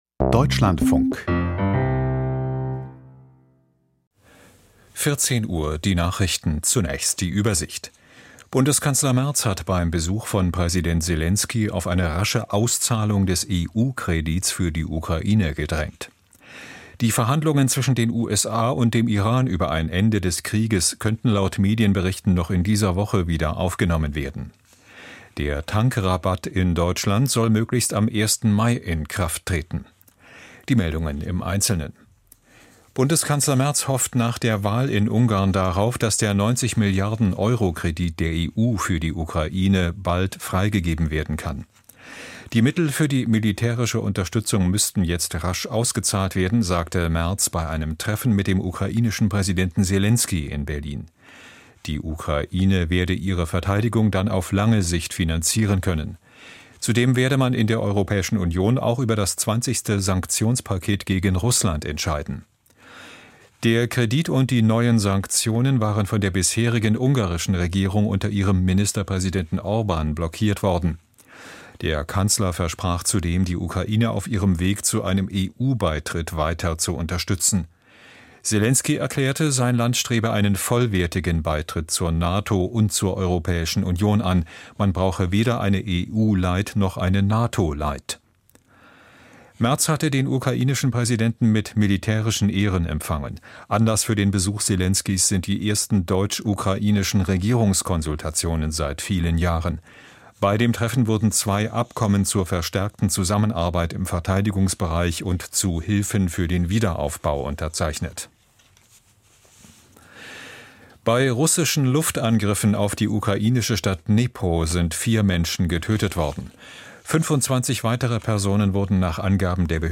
Die Nachrichten vom 14.04.2026, 14:00 Uhr